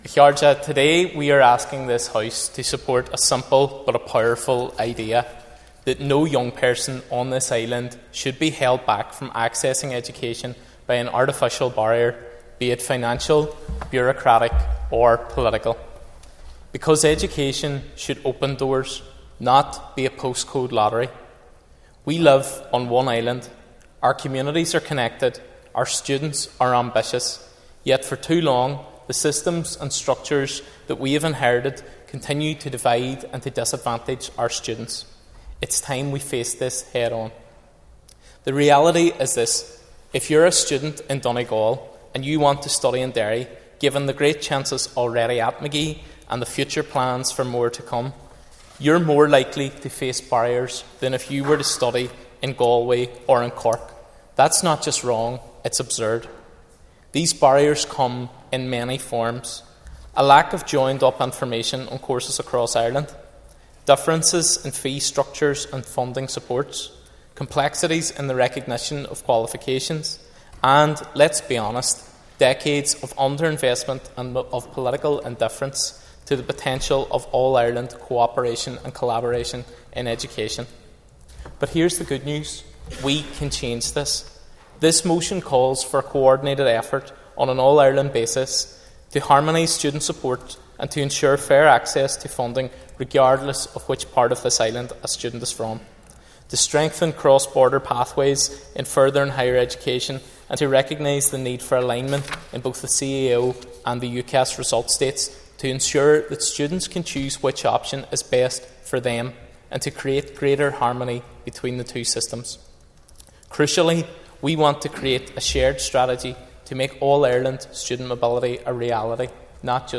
MLA Padraig Delargy was speaking in the chamber this week, after tabling a motion, calling for the breaking down of artificial barriers to education.
MLA Delargy gave the example of Donegal students in his address: